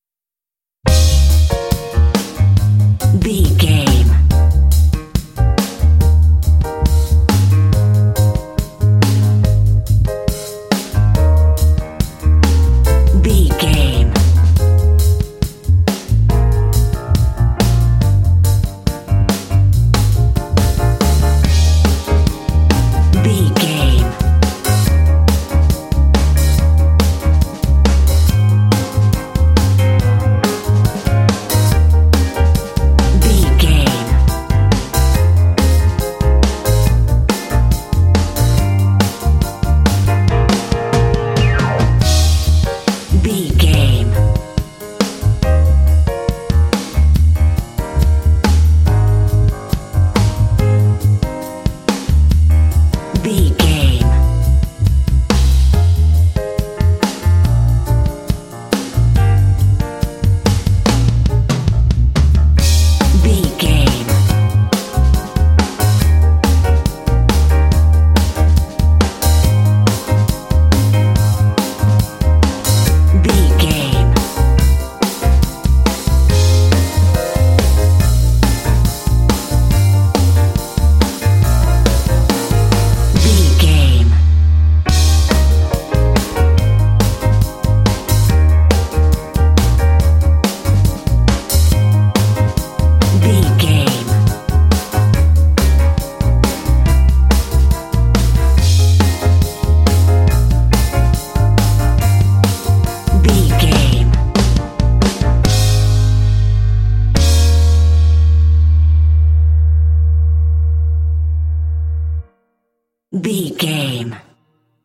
Aeolian/Minor
E♭
funky
groovy
bright
piano
drums
bass guitar
blues
jazz